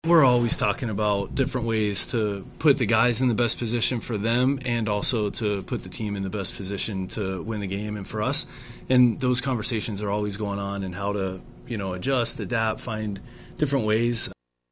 Manager Donnie Kelly says the Pirates are trying to find the right situations to put their younger players, especially their pitchers, in position to succeed.